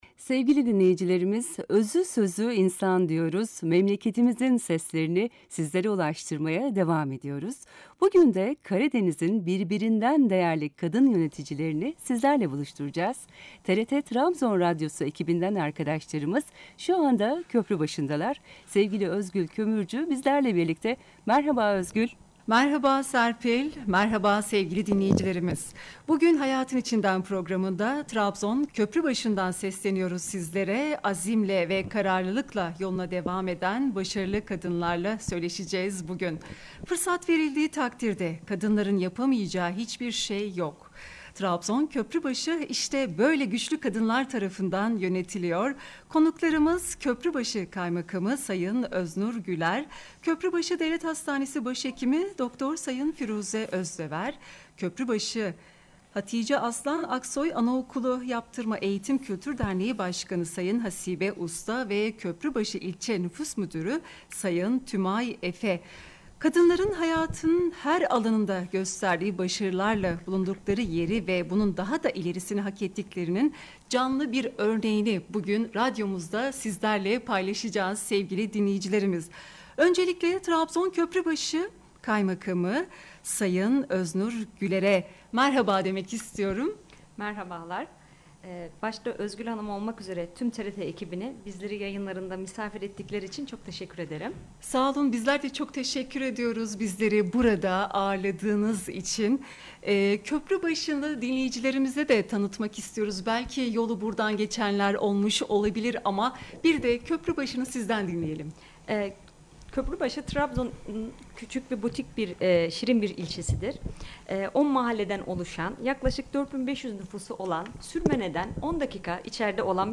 TRT Trabzon Radyosu Köprübaşı’nın Kadın Yöneticilerinin Sesini Duyurdu